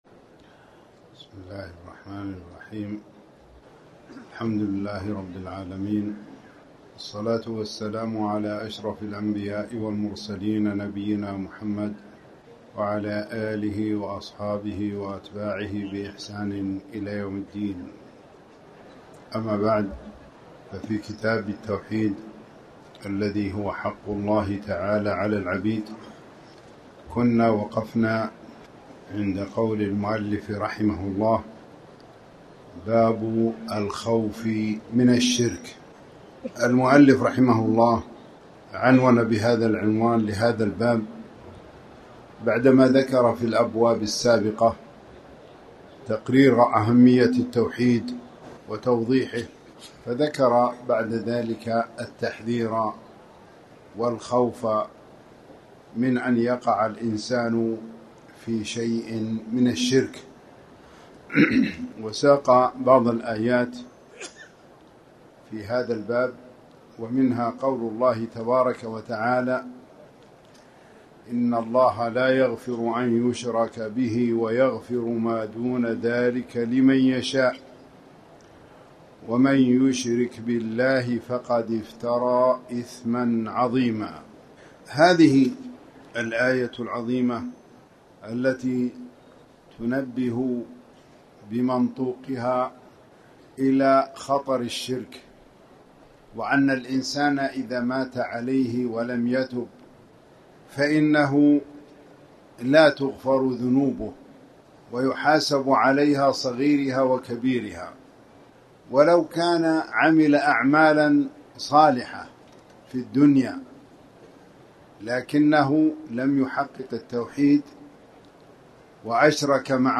تاريخ النشر ١٣ ربيع الثاني ١٤٣٩ هـ المكان: المسجد الحرام الشيخ